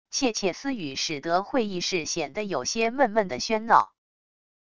窃窃私语使得会议室显得有些闷闷的喧闹wav音频